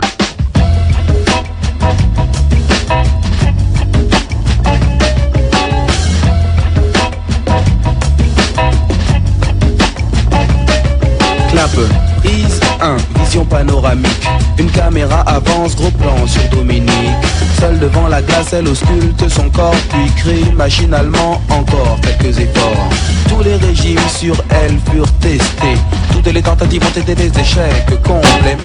une couleur tellement soul et funky